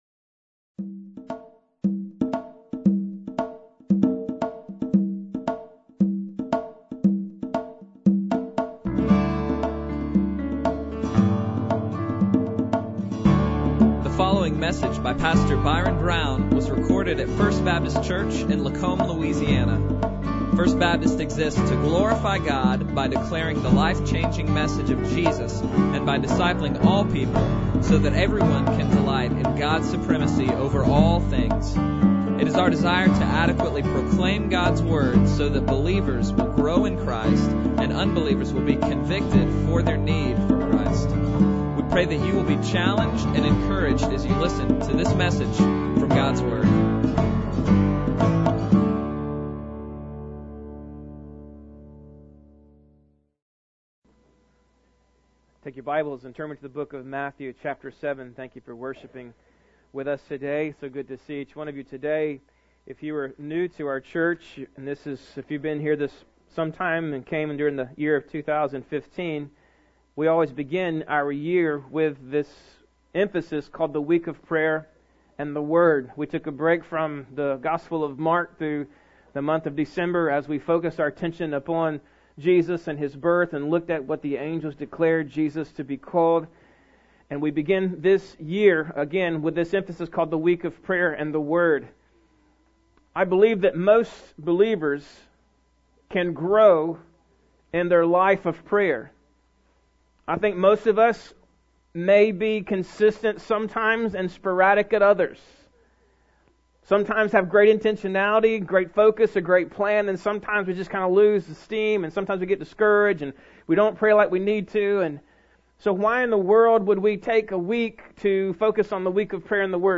Bible Text: Matthew 7:7-11 | Preacher